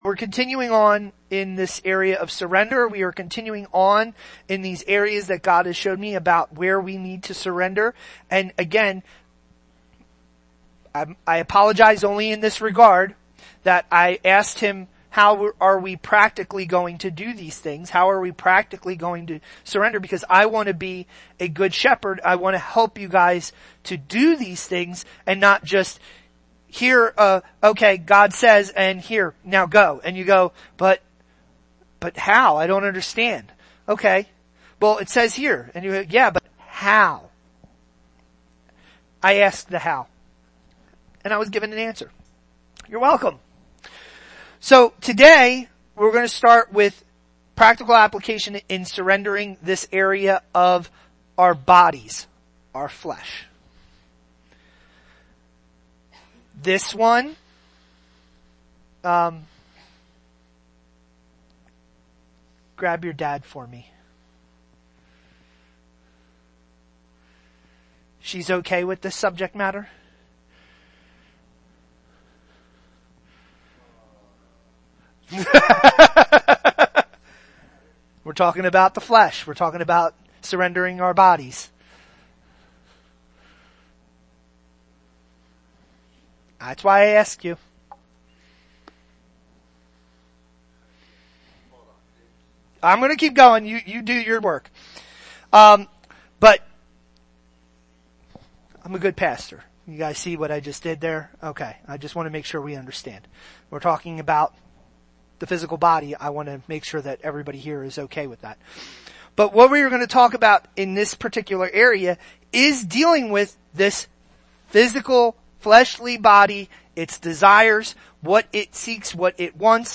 Sermons – Page 4